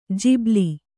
♪ jibli